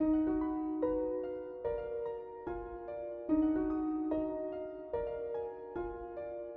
Tag: 146 bpm Trap Loops Piano Loops 1.11 MB wav Key : Unknown